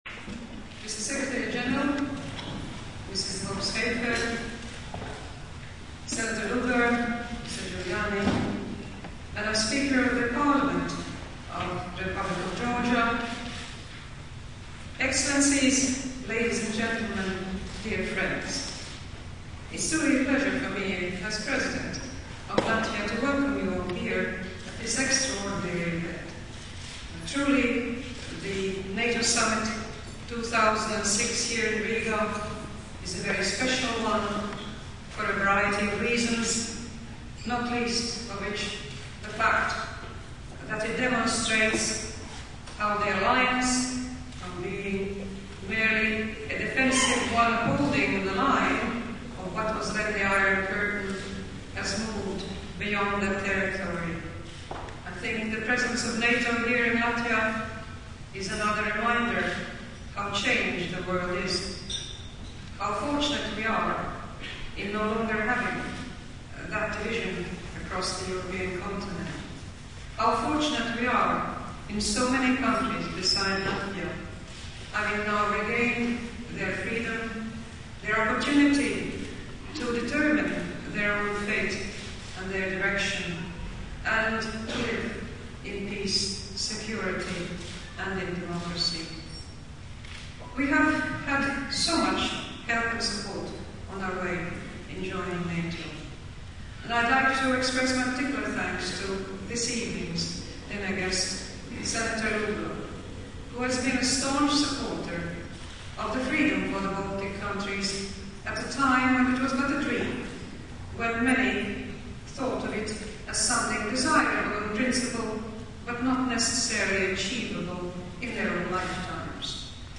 Valsts prezidentes Vairas Vīķes-Freibergas runa prezidentes rīkotās vakariņās par godu Rīgas konferences atklāšanai 2006. gada 27. novembris, Mazā ģilde | Latvijas Valsts prezidenta mājaslapa
Valsts prezidentes uzruna vakariņās par godu Rīgas konferences atklāšanai